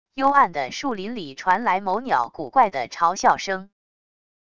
幽暗的树林里传来某鸟古怪的嘲笑声wav音频